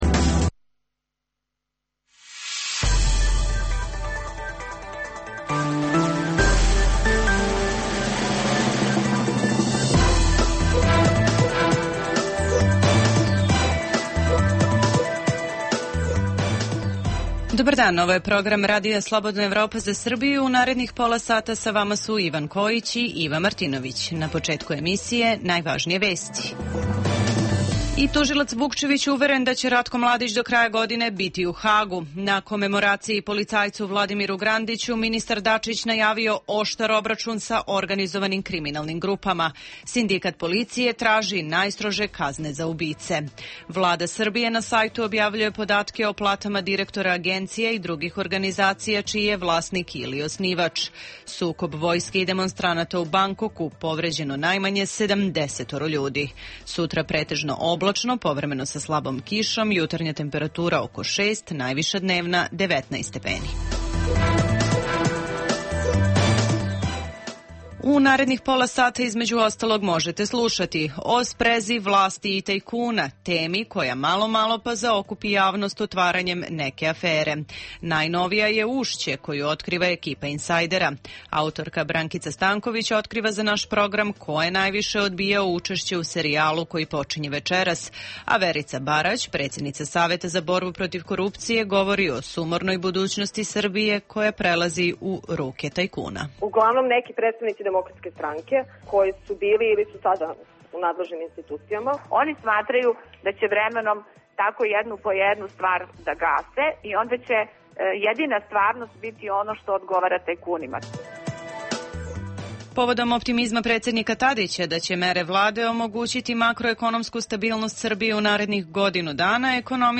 Emisija namenjena slušaocima u Srbiji.